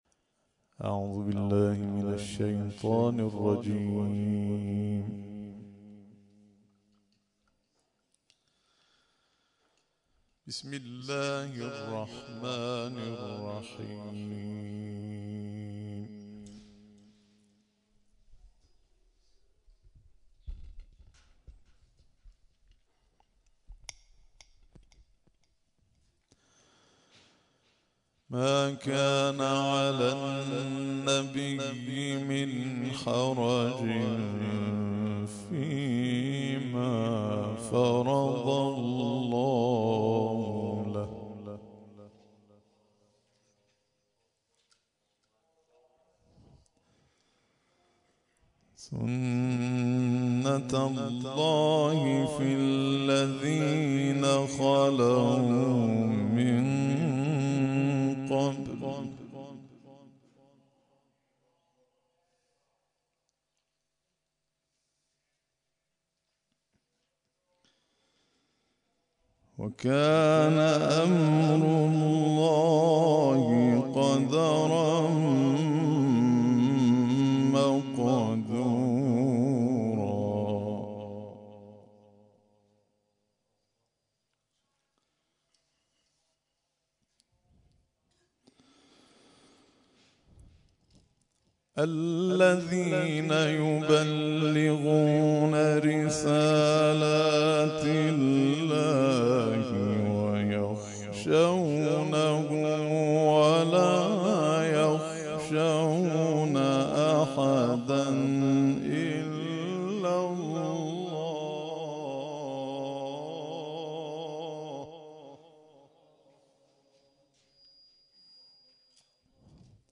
روز گذشته 300‌اُمین کرسی تلاوت نفحات در مسجد صاحب‌الزمان(عج) خانی‌آباد نو برگزار شد